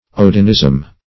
Search Result for " odinism" : The Collaborative International Dictionary of English v.0.48: Odinism \O"din*ism\, n. Worship of Odin; broadly, the Teutonic heathenism.
odinism.mp3